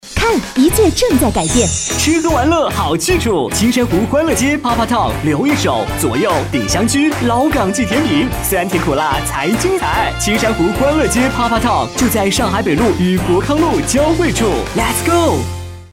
标签： 成熟
配音风格： 成熟 稳重